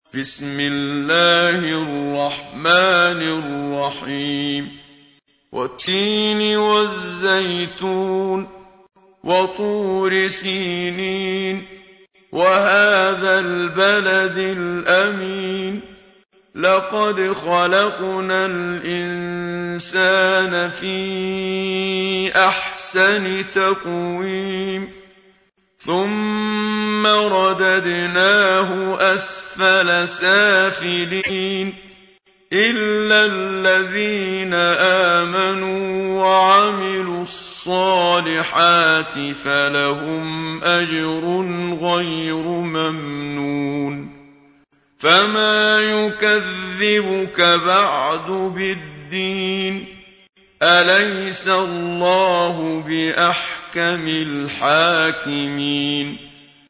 تحميل : 95. سورة التين / القارئ محمد صديق المنشاوي / القرآن الكريم / موقع يا حسين